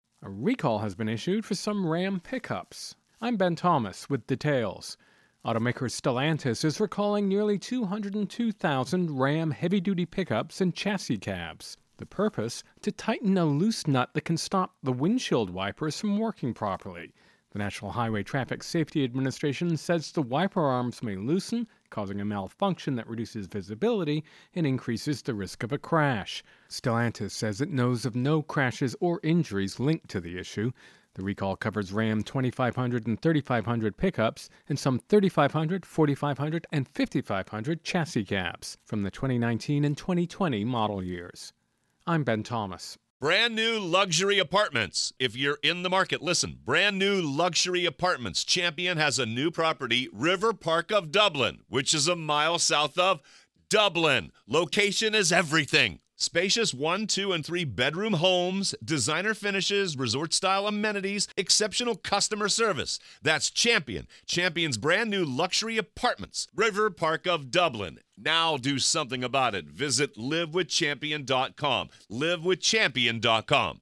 Intro and voicer "Ram Truck Recall"